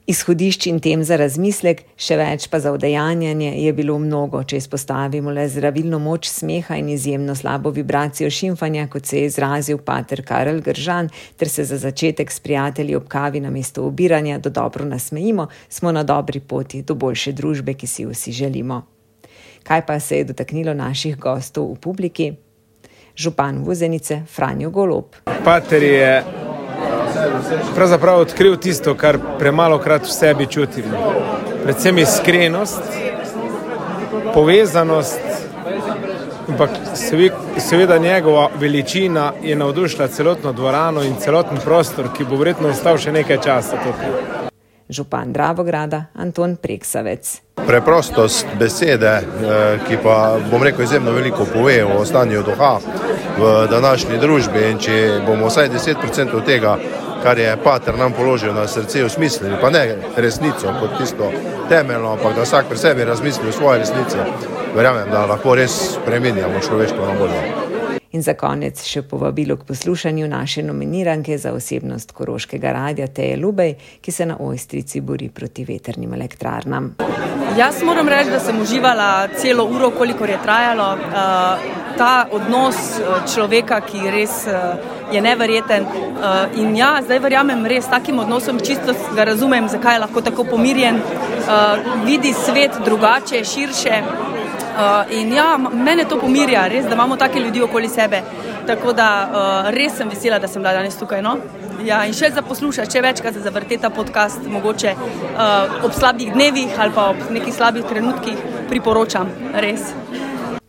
Kaj pa se je dotaknilo naših gostov v publiki: